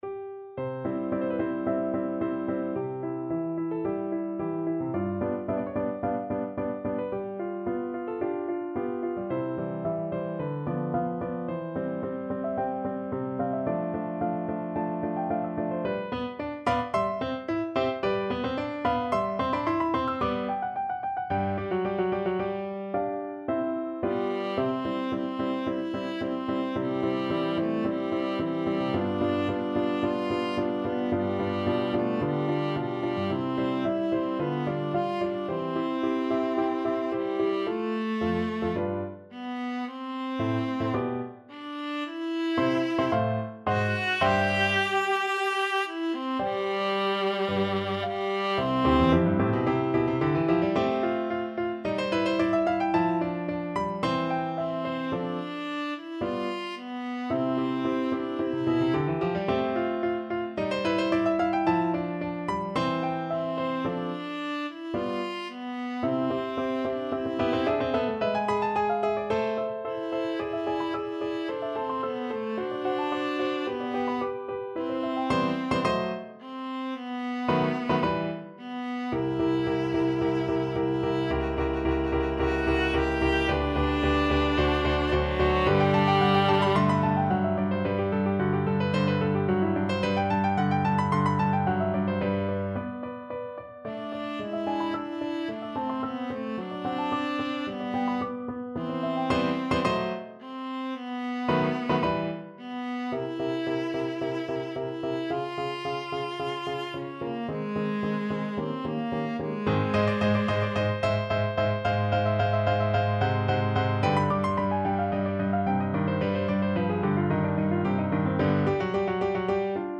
Viola version
4/4 (View more 4/4 Music)
Andante =110
Classical (View more Classical Viola Music)